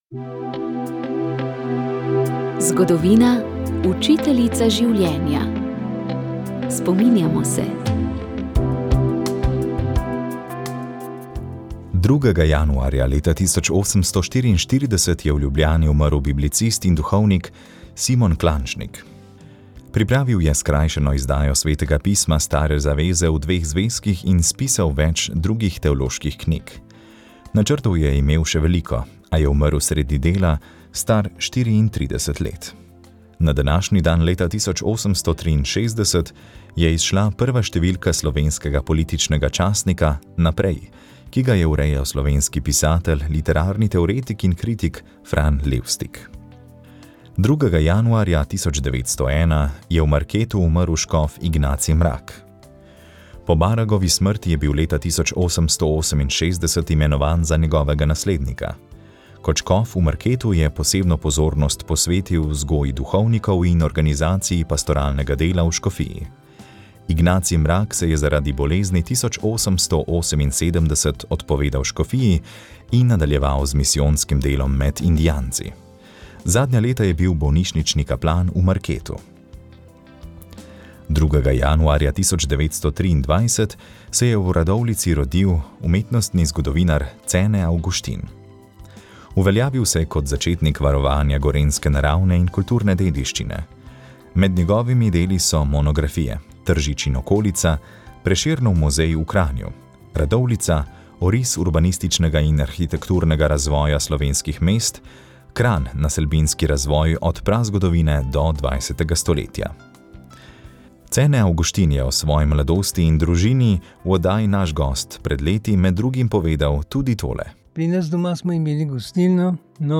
Ustanovila sta jih sveti Vincencij Pavelski in sveta Ludovika de Marillac, v Slovenijo pa jih je pripeljala sestra Leopoldina Jožefa Brandis. Praznovanje je potekalo pri Svetem Jožefu v Celju.